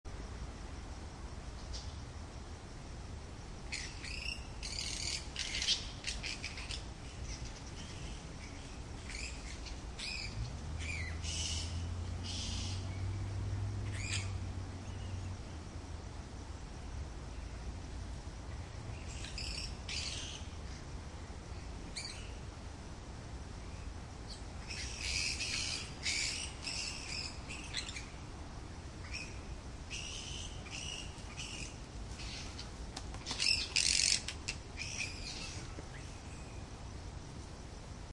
Fruit Bats Botón de Sonido
Animal Sounds Soundboard138 views